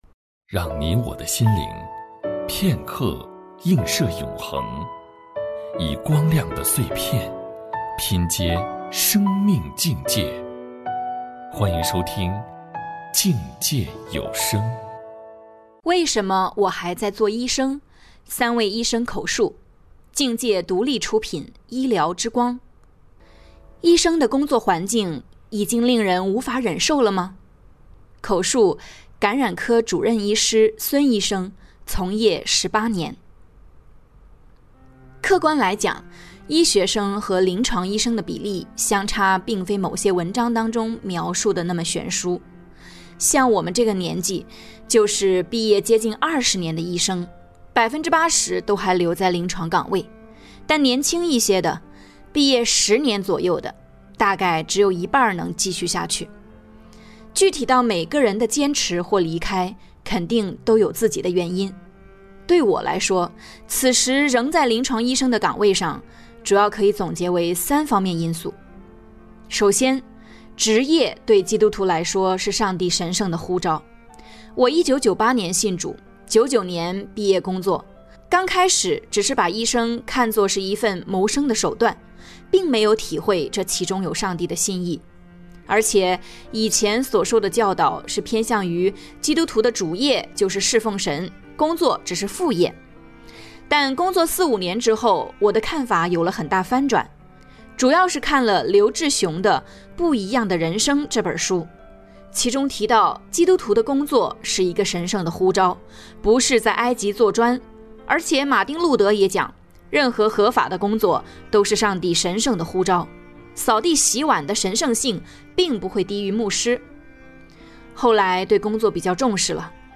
采访
播音